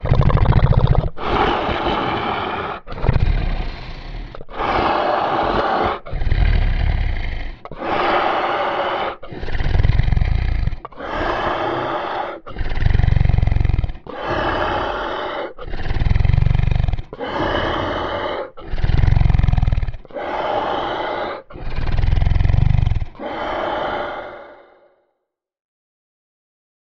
Звуки чудовища
Звук: чудовище храпит, во сне так дышит